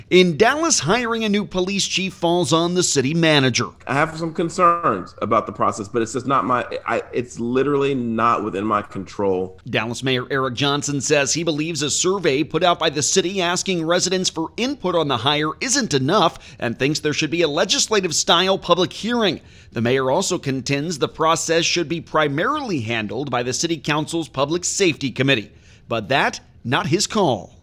“I have some concerns about the process, but it’s literally not in my control,” Mayor Johnson told WBAP/KLIF during a ‘Year End Conversation’ hosted by the Dallas Regional Chamber on Thursday.